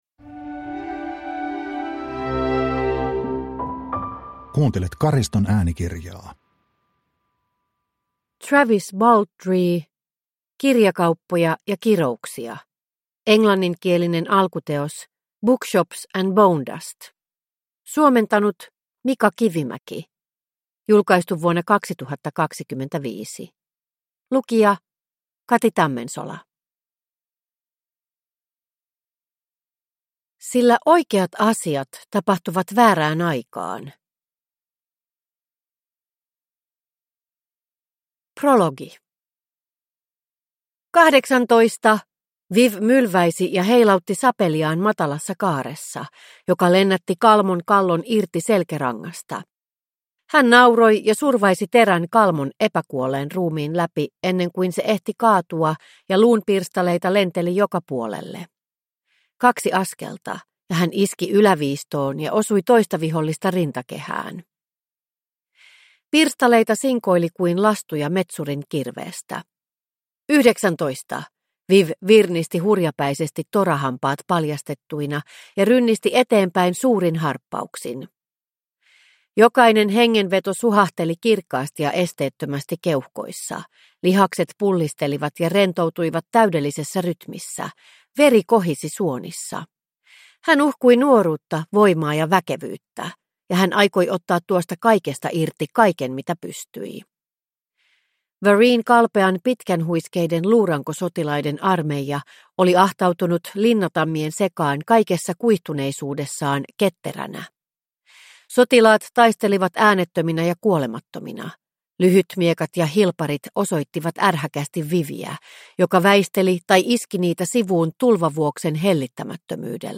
Kirjakauppoja ja kirouksia – Ljudbok